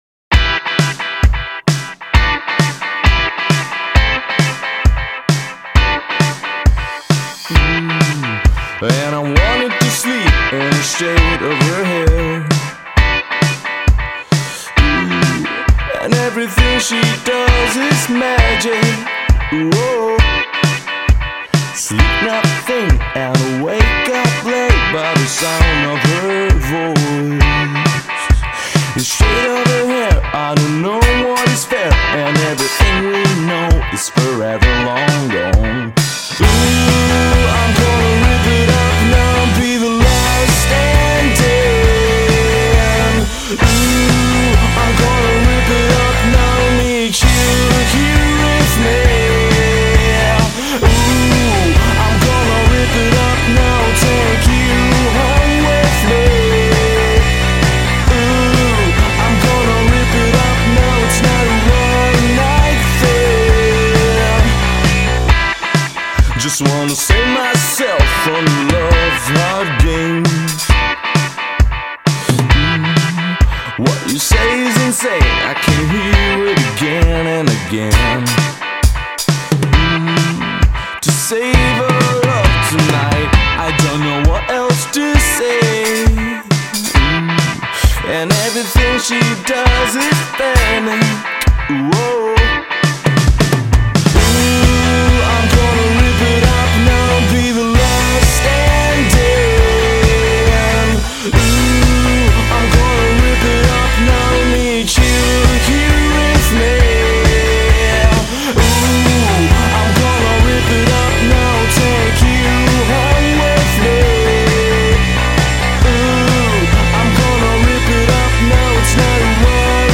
Žánr: Pop
Chytlavé melodie, snové synth plochy a výrazná rytmika